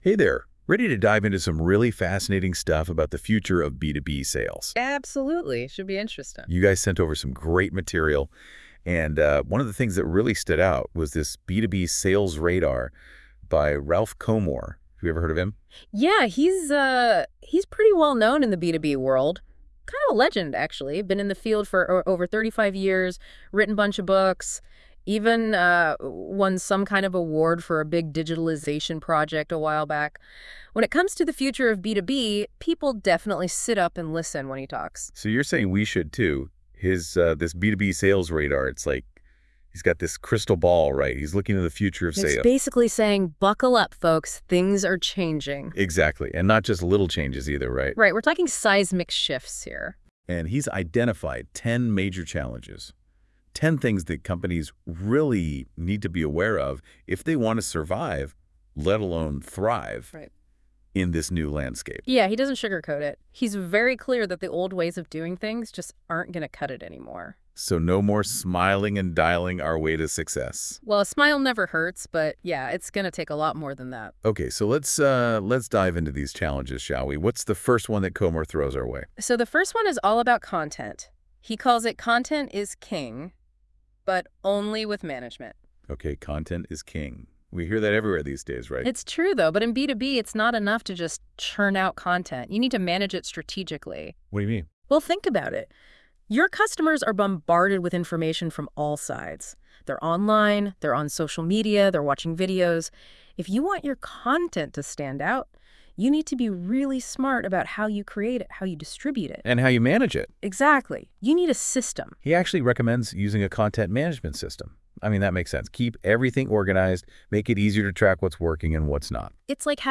Listen to a podcast generated by artificial intelligence on this topic, enriched with fictional situations: